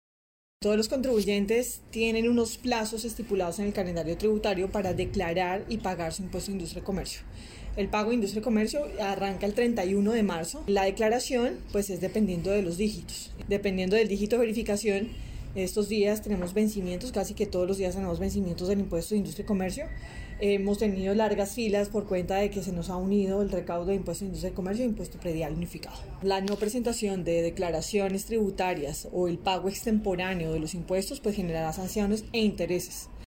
Saharay Rojas, secretaria de Hacienda de Bucaramanga
Saharay-Rojas-Secretaria-de-Hacienda-de-Bucaramanga-3.mp3